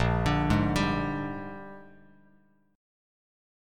Bb13 chord